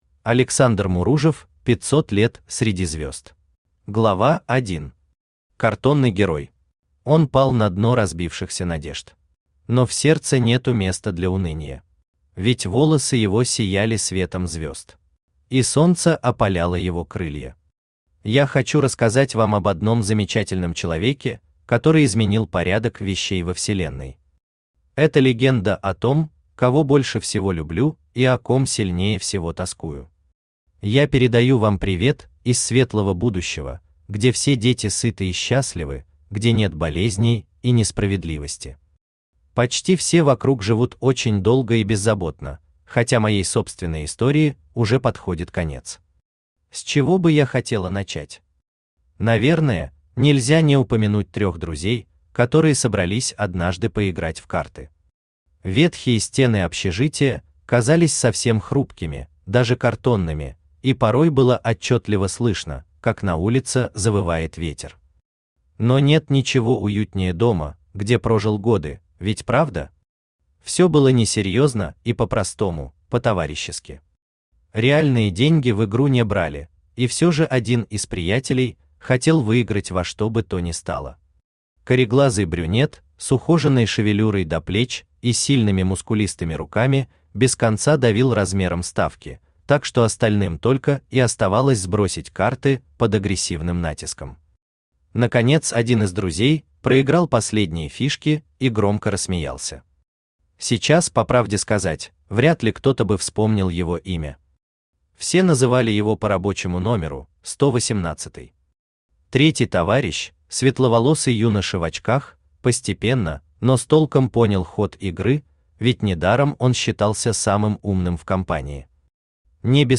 Аудиокнига Пятьсот лет среди звезд | Библиотека аудиокниг
Aудиокнига Пятьсот лет среди звезд Автор Александр Олегович Муружев Читает аудиокнигу Авточтец ЛитРес.